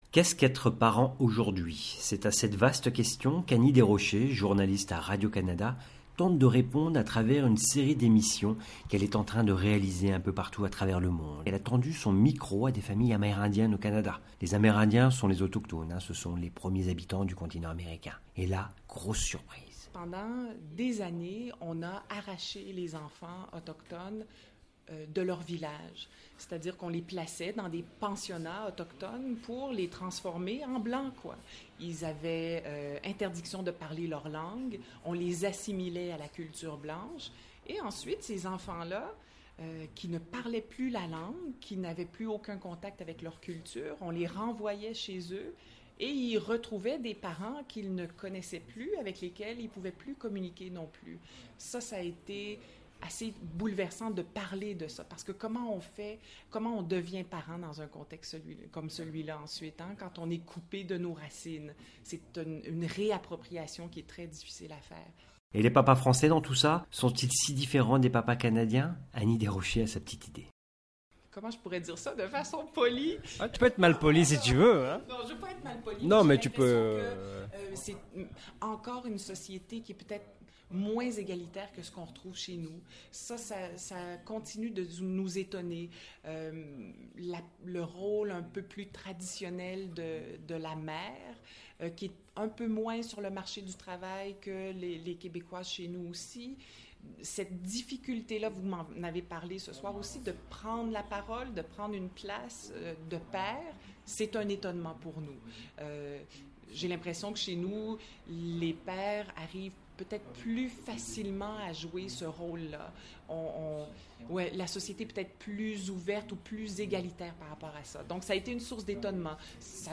Pour la réaliser, elle a tendu son micro à des parents vivant dans différentes provinces canadiennes, en Suède, en Afrique, aux Etats-Unis et en France, où, cette fois-ci, c'est moi qui lui ai posé quelques questions.